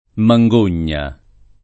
[ ma jg1 n’n’a ]